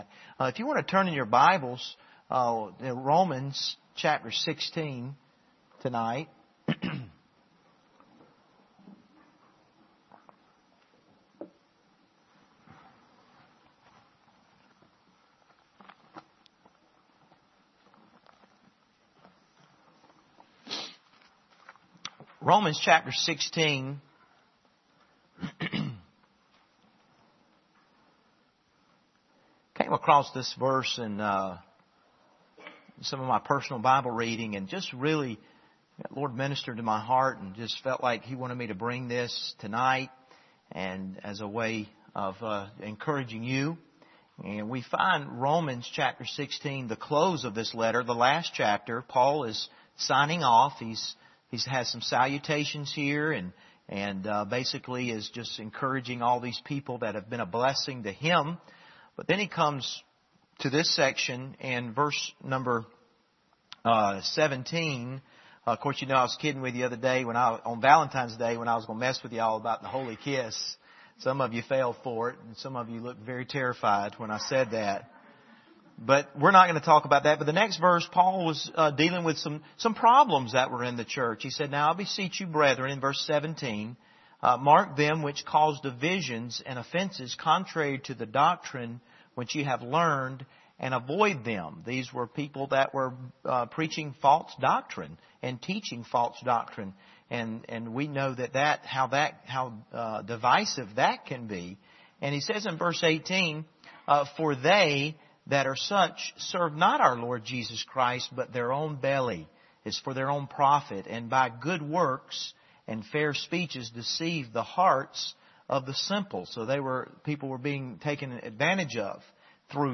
Passage: Romans 16:17-20 Service Type: Wednesday Evening